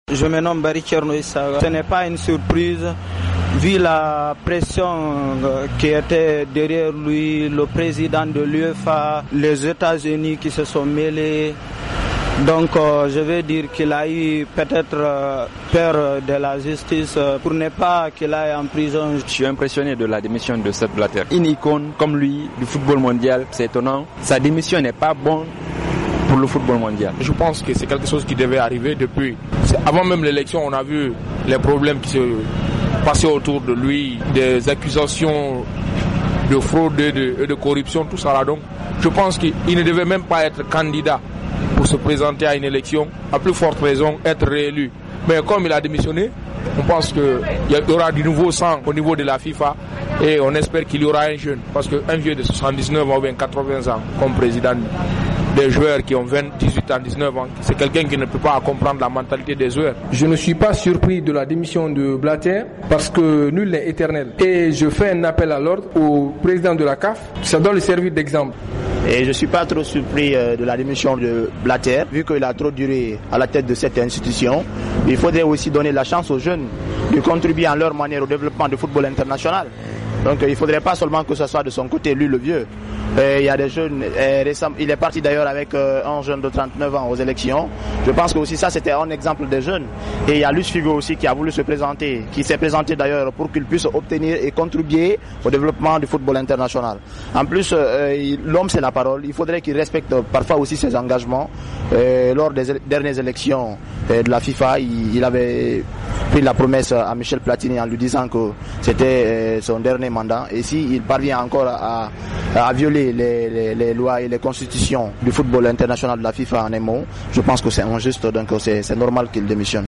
a baladé son micro dans la capitale guinéenne :
Réactions recueillies